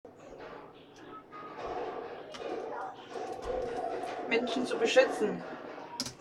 MS Wissenschaft @ Diverse Häfen
Mensch hat auf die Frage: Was ist Dir Freiheit wert? geantwortet. Standort war das Wechselnde Häfen in Deutschland.